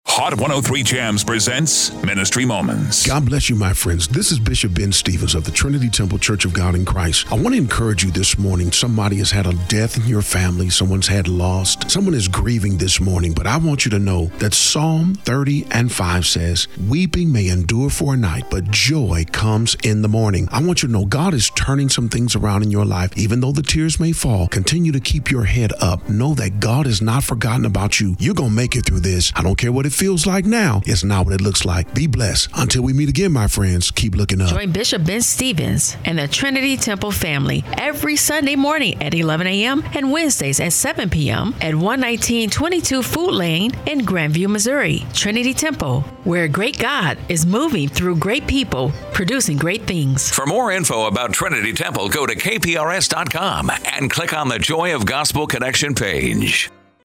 Radio Broadcast